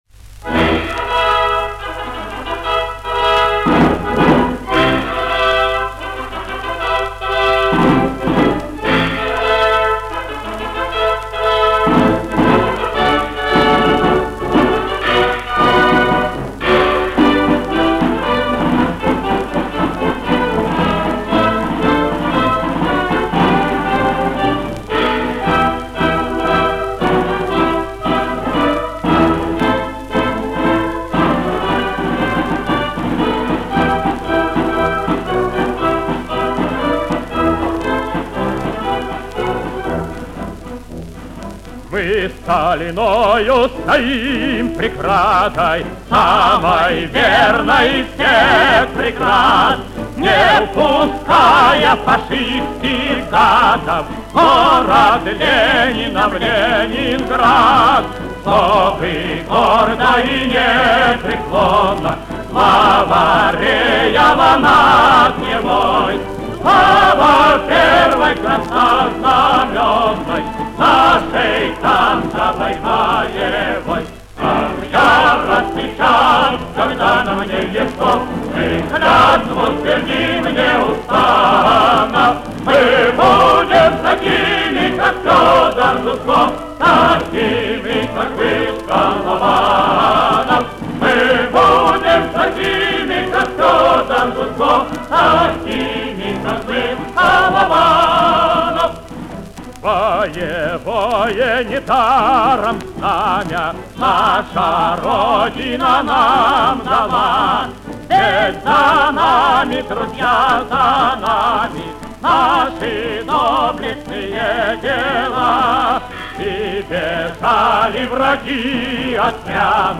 Описание: Редкая запись с блокадной пластинки